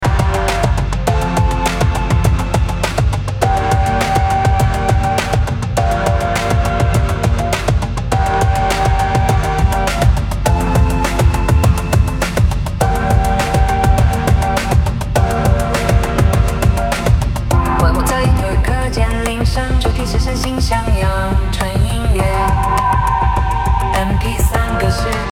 帮我制作一个课间铃声，主题是身心向阳，纯音乐,MP3格式